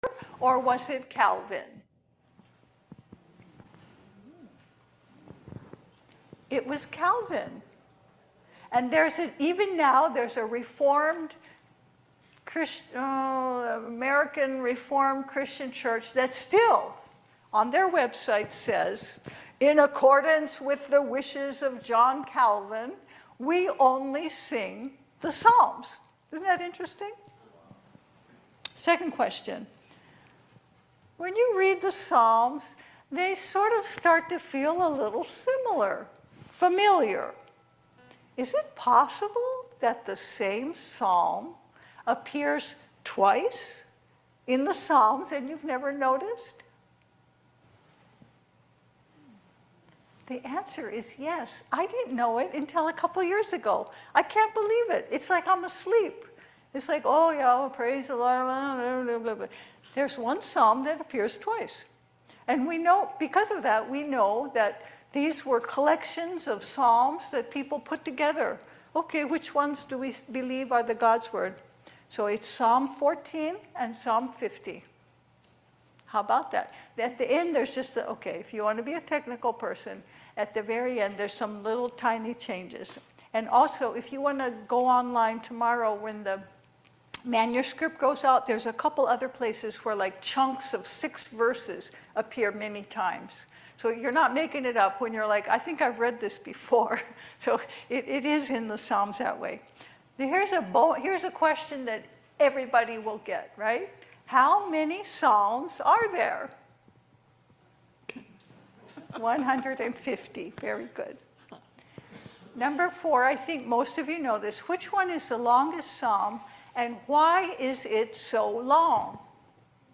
Psalm 146 Service Type: Sunday Service I will praise the LORD as long as I live